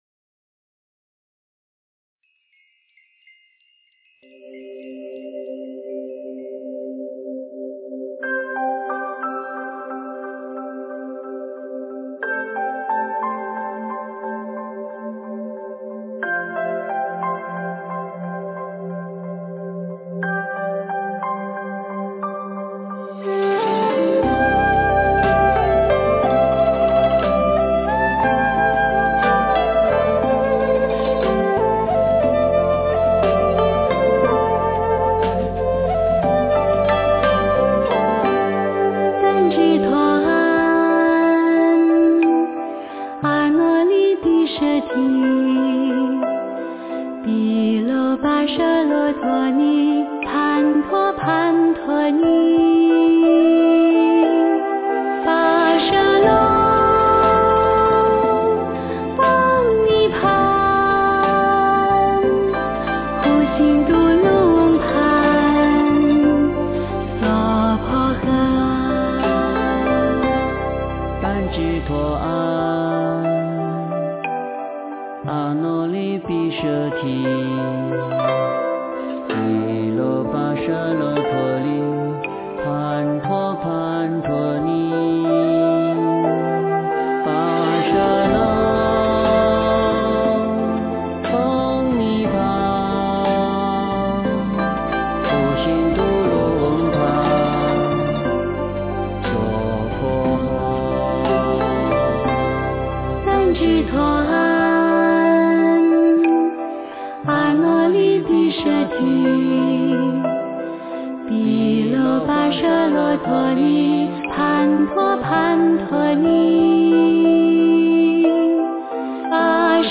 诵经
佛音 诵经 佛教音乐 返回列表 上一篇： 般若波罗蜜多心经 下一篇： 准提咒 相关文章 南无本师释迦牟尼佛--浴佛颂 南无本师释迦牟尼佛--浴佛颂...